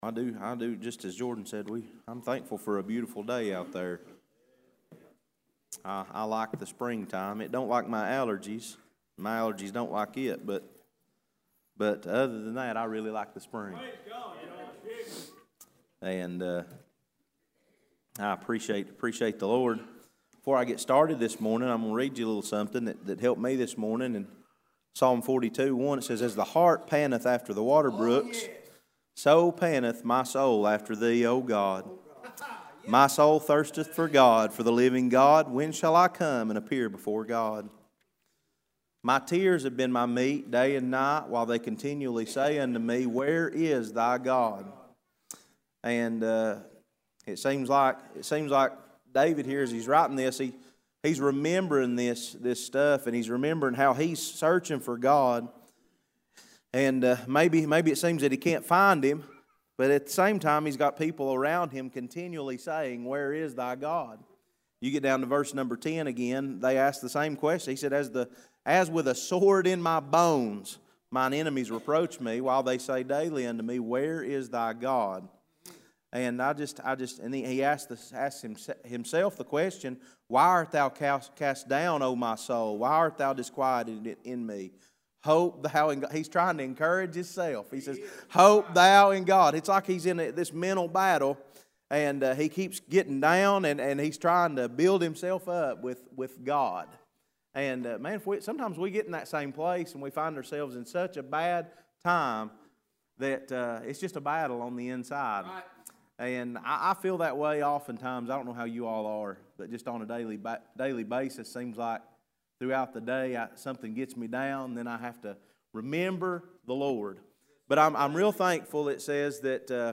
Passage: 1 John 4: 5-6 Service Type: Sunday School